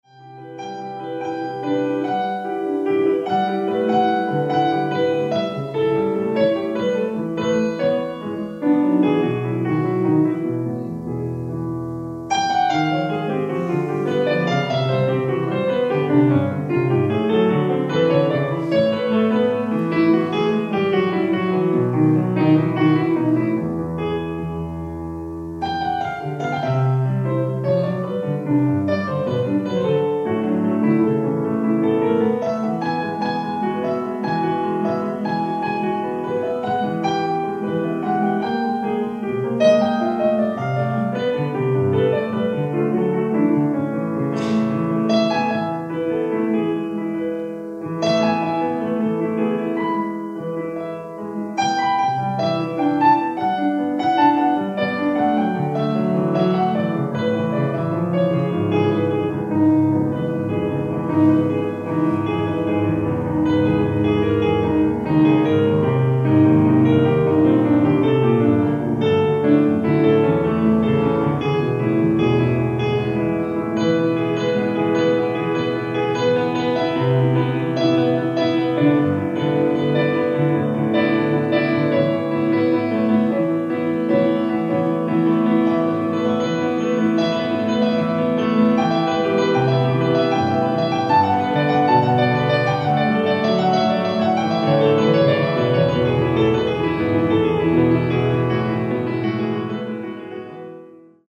ライブ・アット・ウィグモアホール、ロンドン 02/09/2025
※試聴用に実際より音質を落としています。